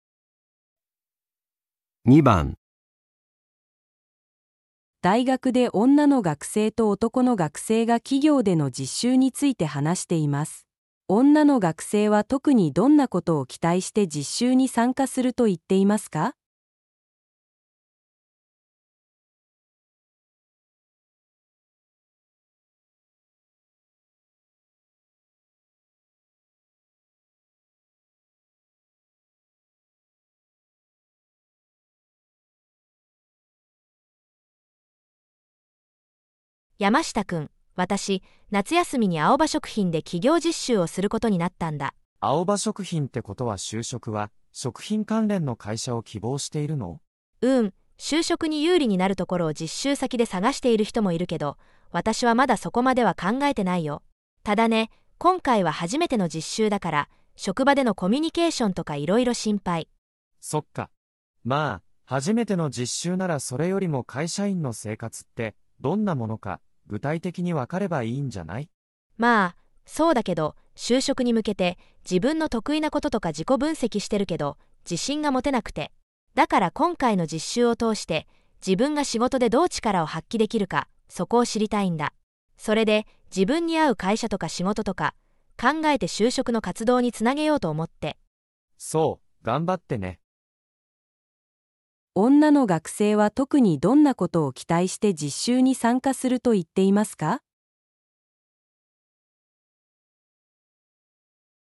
在大学里，一位女大学生和一位男大学生正在谈论企业实习的事情。